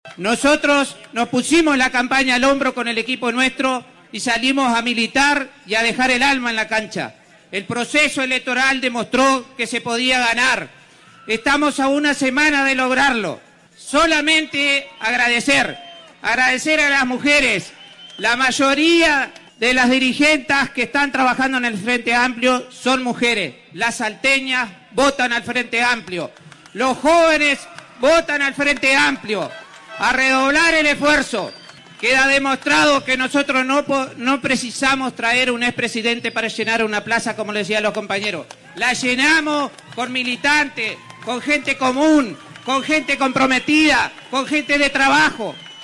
Multitudinario acto en la Plaza Estigarribia